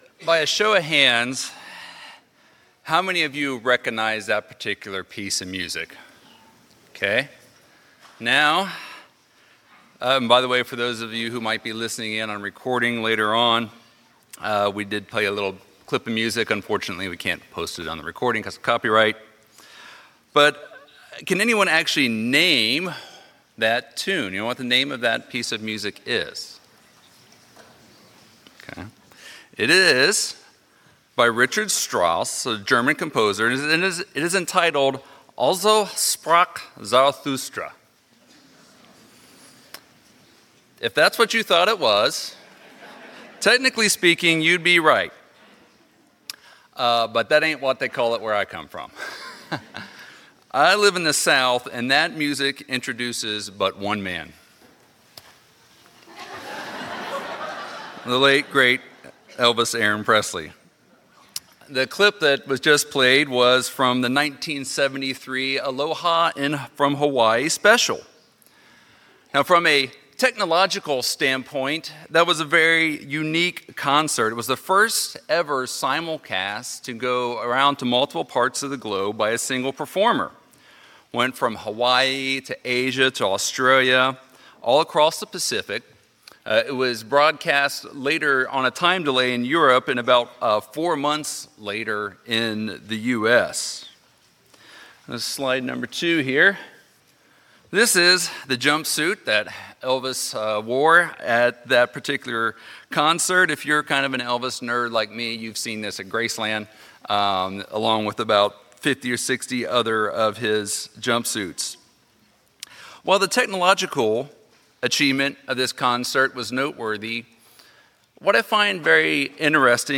Sermons
Given in Lihue, Hawaii